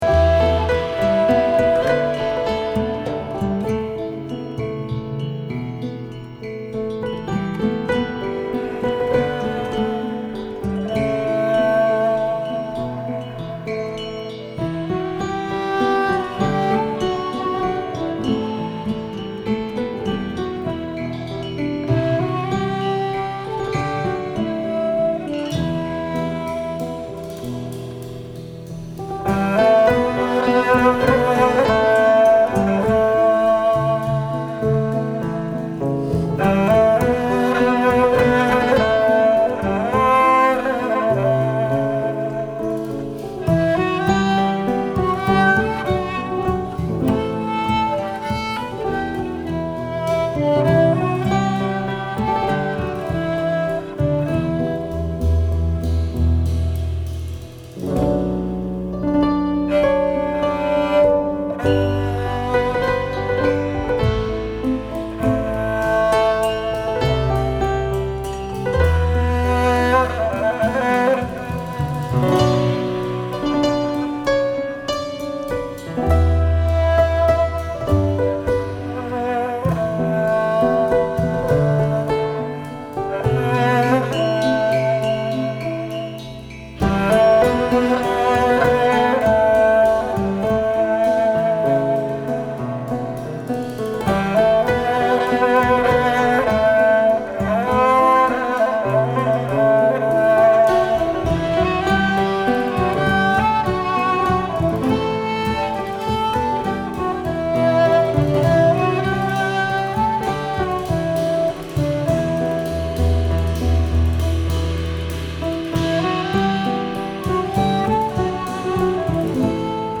kamânche
pianoforte, harmonium
violone, contrebasse
percussions